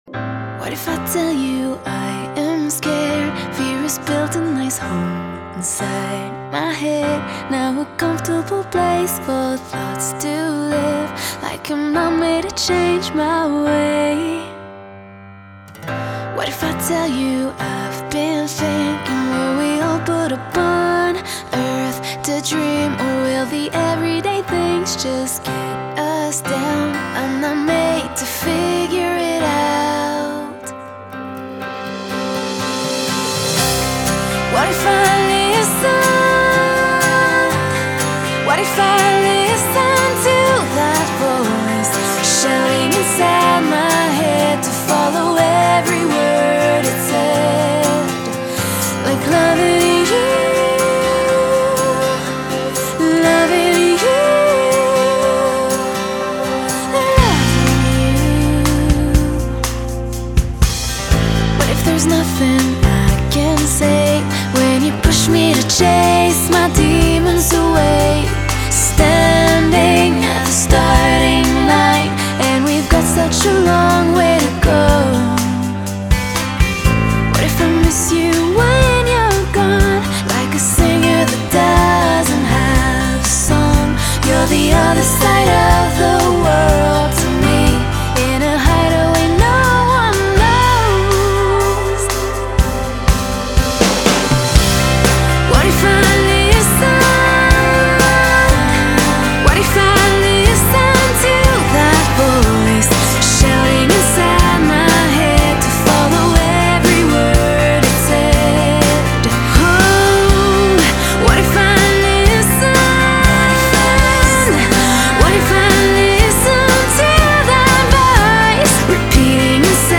Genre.........................: Pop